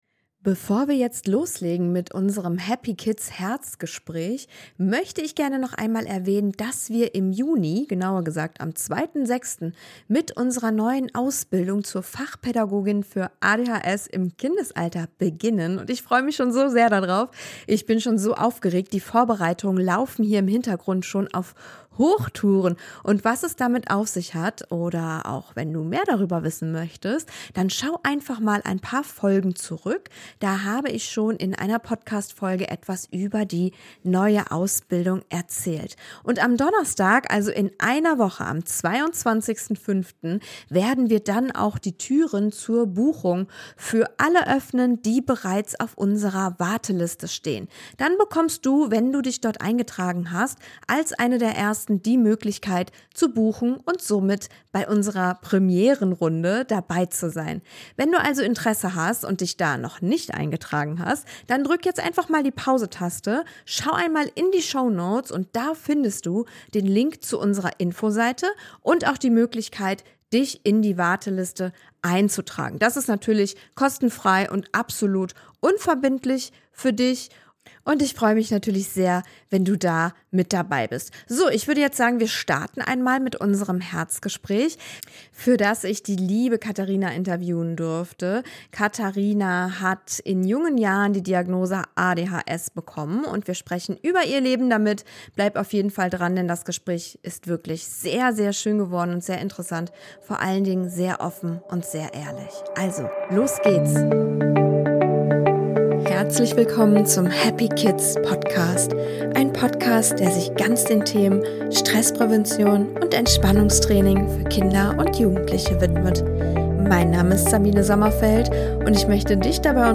Offen, ehrlich, empathisch und voller wertvoller Impulse.